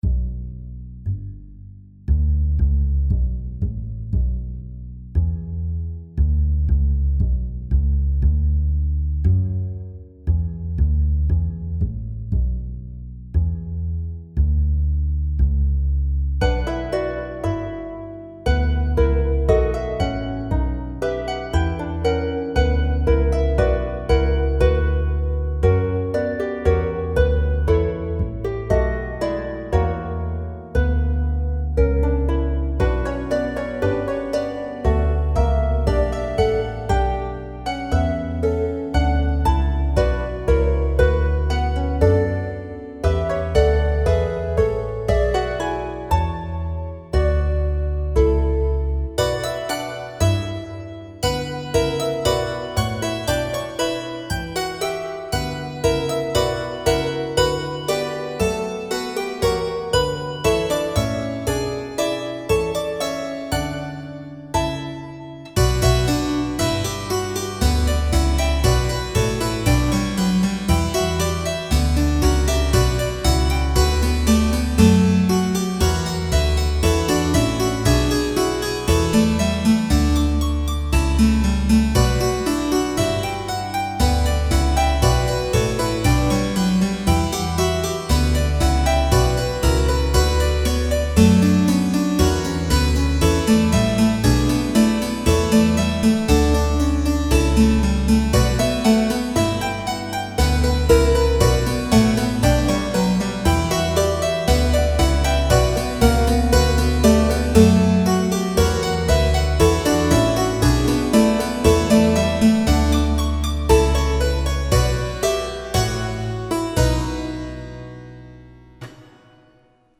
Interessanter ist vielleicht das andere Stück, eine Passacaglia, also Variationen auf eine wiederkehrende, unveränderte und daher etwas monotone Basslinie, so monoton wie das Leben in diesen Osterferien. Allerdings kann man auch daraus etwas machen, wenn man über die Basslinien einfach ein paar extravagante Melodien drüberkomponiert, mit schönen komplizierten Rhythmen und Kadenzen, damit man sich trotzdem nicht langweilt.
Die zweite Version kommt etwas mehr orchestriert daher mit einem gezupften Jazz-Kontrabass, keltischen Renaissanceharfen, Dulcimeren, Kantelen und wieder dem Barockvirginal – trotz Fehlen echter Streicher wirkt das Ganze dann auch gleich viel satter:
Passacaglia in h-Moll Orchesterversion
Es spielt wie immer das Niarts Hausorchester, also unsere Samplebibliothek.